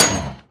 sounds / mob / blaze / hit2.mp3
hit2.mp3